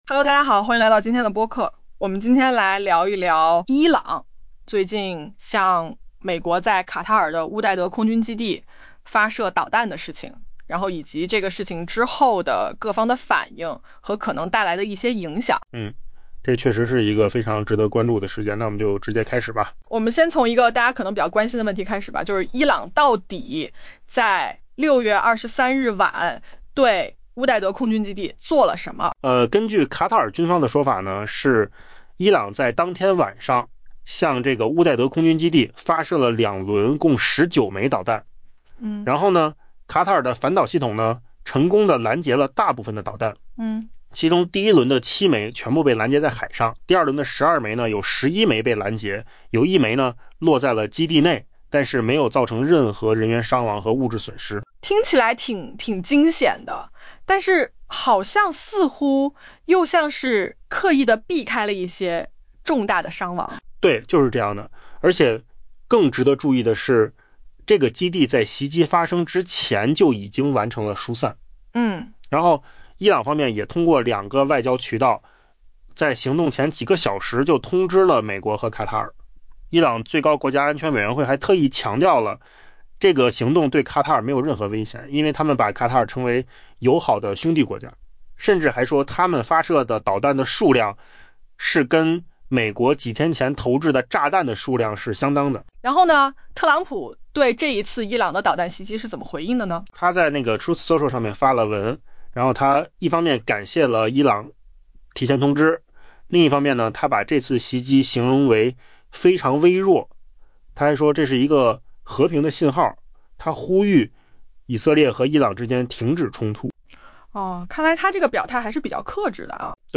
AI播客：換個方式聽新聞（音頻由釦子空間生成） 下載mp3